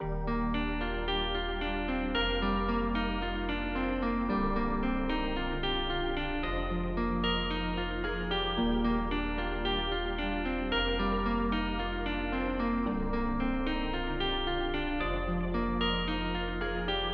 气氛
标签： 112 bpm Ambient Loops Fx Loops 2.88 MB wav Key : Unknown
声道立体声